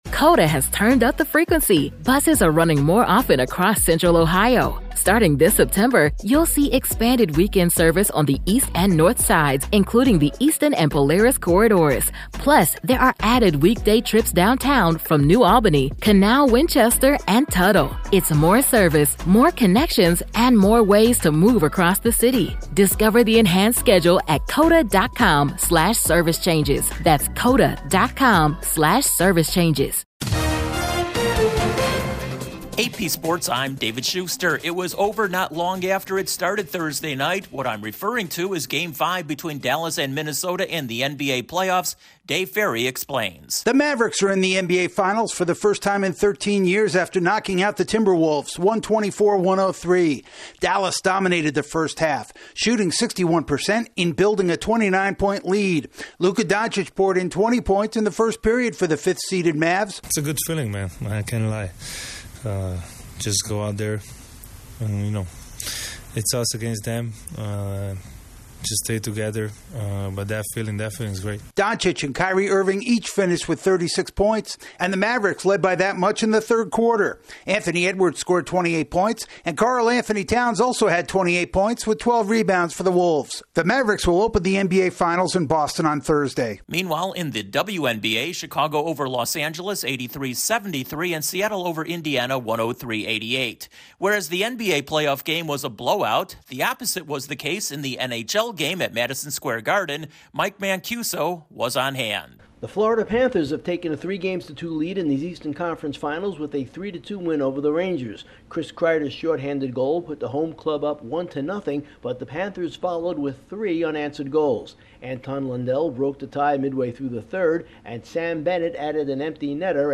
The Mavericks advance to the NBA Finals, another nail biter at Madison Square Garden in hockey, eight games in Major League Baseball and Hard Knocks has its team for the upcoming NFL season. Correspondent